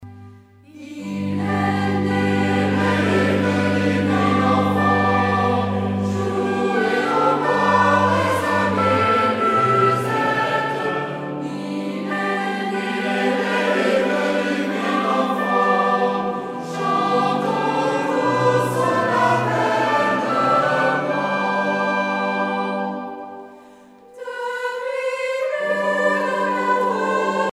Genre strophique Artiste de l'album Psalette (Maîtrise)
Pièce musicale éditée